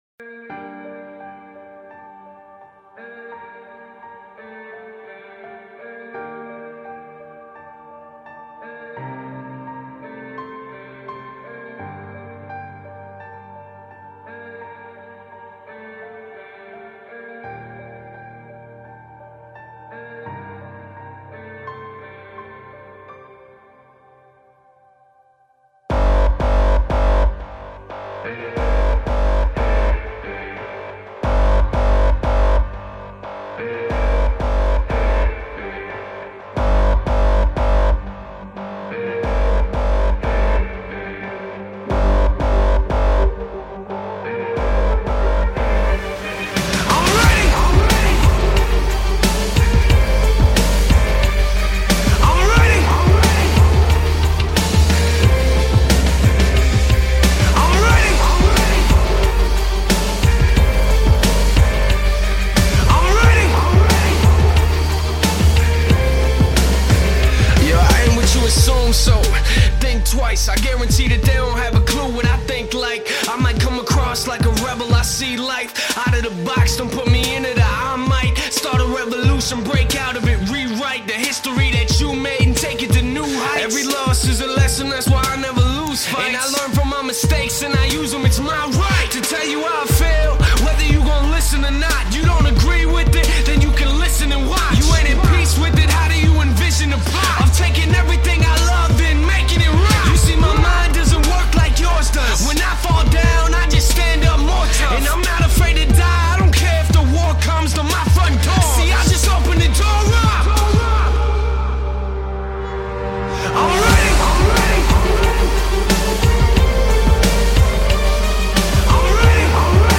دانلود آهنگ سبک هیپ هاپ